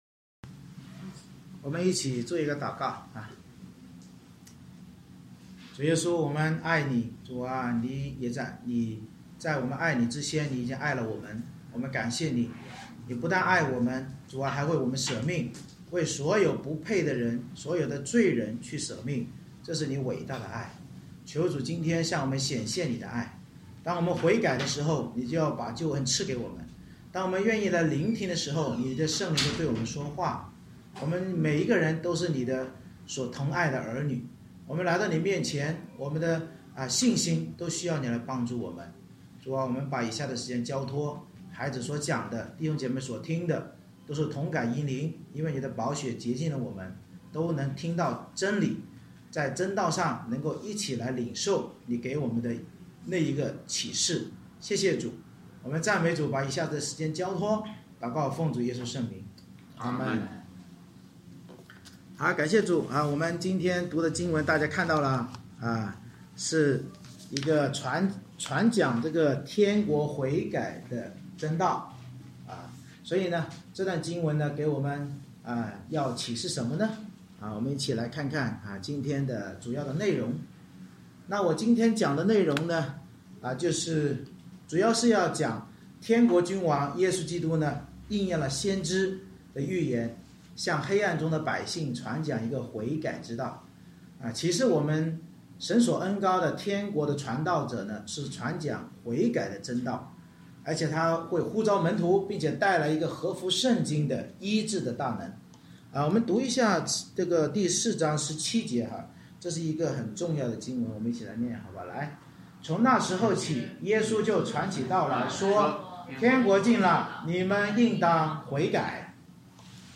太4:12-25 Service Type: 主日崇拜 天国君王耶稣应验先知预言向黑暗中百姓传讲悔改之道，启示我们神所恩膏的天国传道者传讲悔改真道必呼召门徒并带来合乎圣经的医治大能。